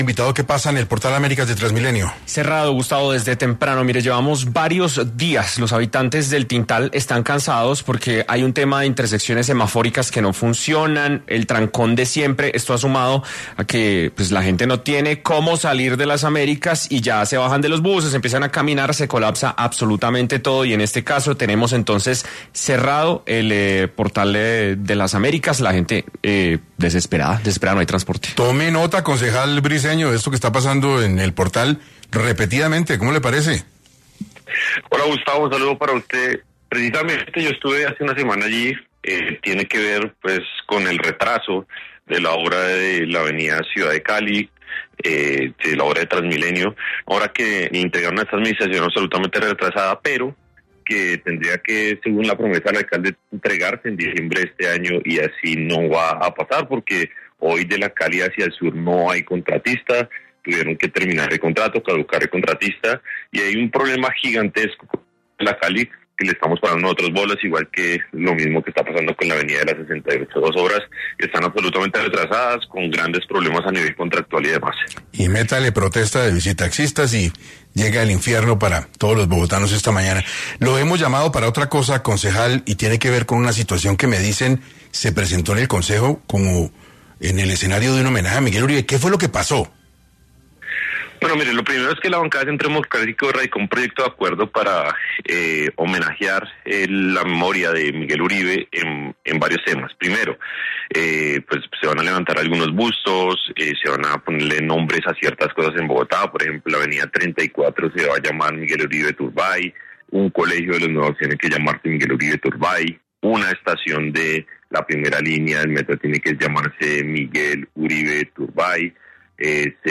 En Caracol Radio estuvo el concejal Daniel Briceño conversando sobre el plan de trabajo de su partido.